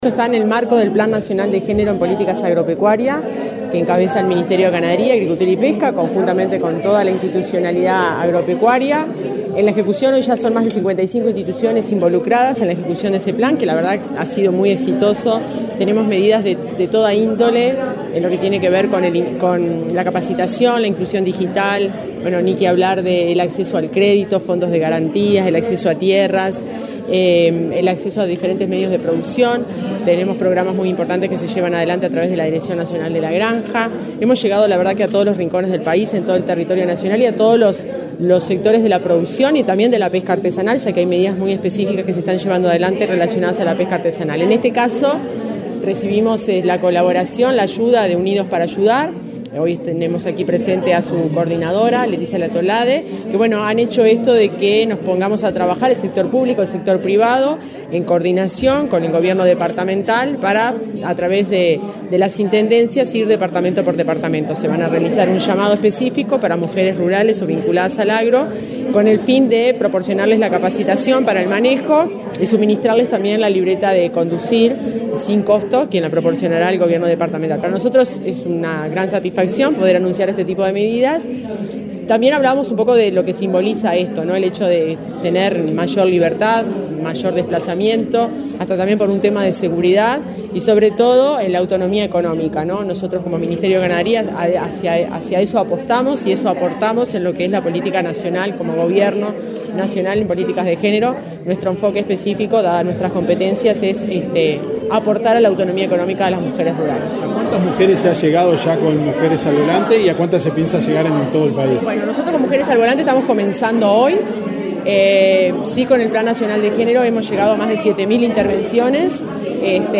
Declaraciones de la directora general del MGAP, Fernanda Maldonado
Declaraciones de la directora general del MGAP, Fernanda Maldonado 06/08/2024 Compartir Facebook X Copiar enlace WhatsApp LinkedIn La directora general del Ministerio Ganadería Agricultura y Pesca (MGAP), Fernanda Maldonado, participó, de la convocatoria “Mujeres al volante”, para el departamento de Colonia, en el marco del Plan Nacional de Género y Políticas Agropecuarias. Luego, dialogó con la prensa.